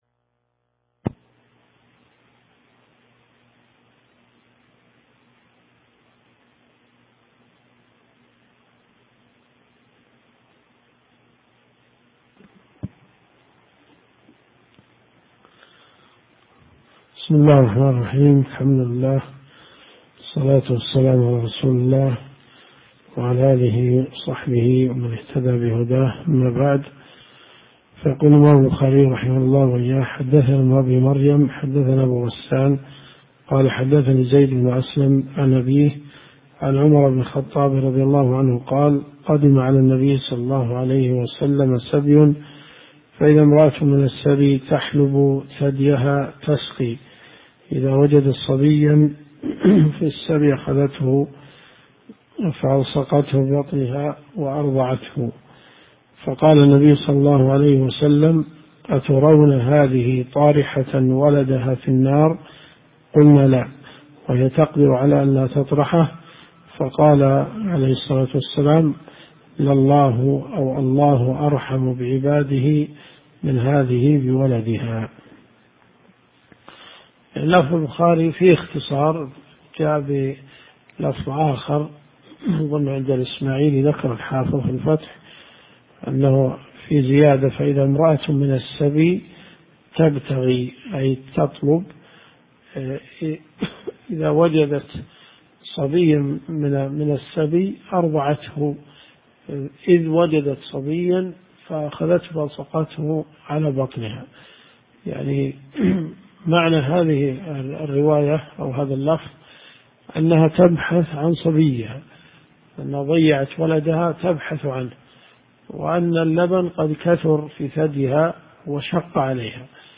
الرئيسية الدورات الشرعية [ قسم الحديث ] > كتاب الأدب من صحيح البخاري . 1430 + 1431 .